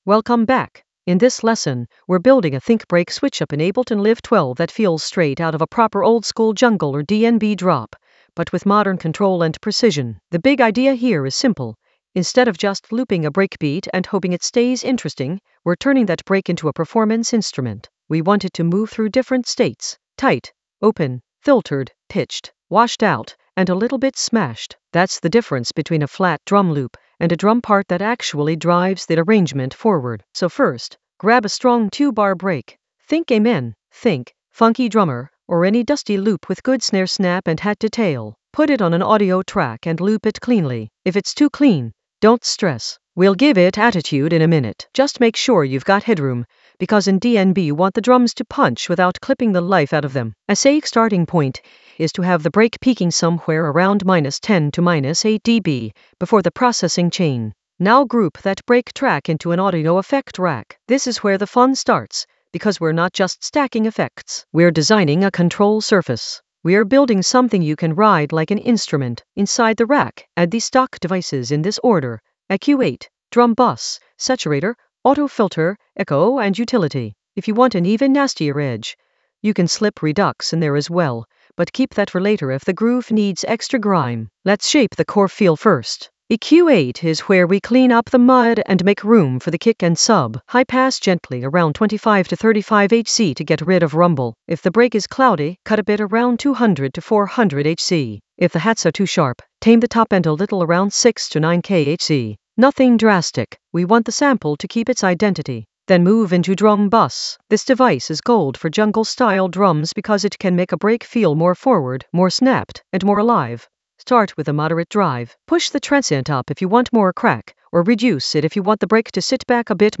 An AI-generated intermediate Ableton lesson focused on Stack a think-break switchup with macro controls creatively in Ableton Live 12 for jungle oldskool DnB vibes in the FX area of drum and bass production.
Narrated lesson audio
The voice track includes the tutorial plus extra teacher commentary.